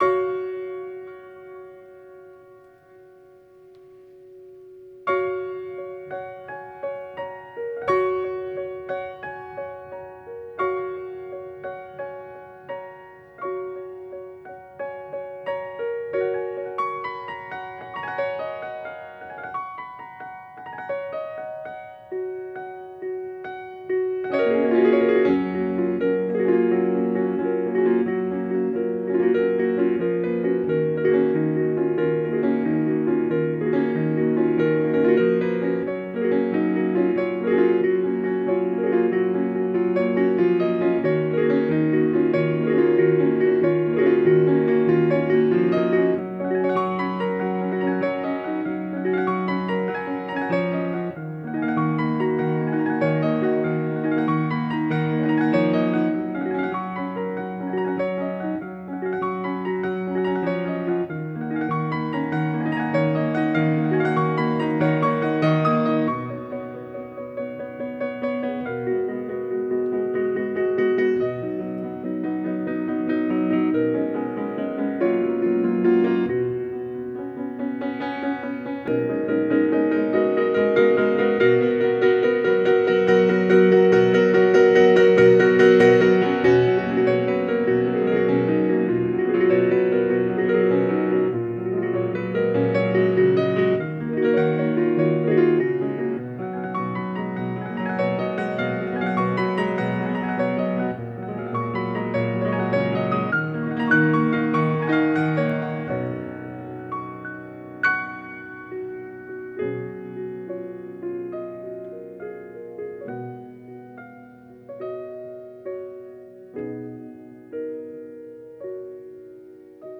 BPM50-152
sorrow feeling, expression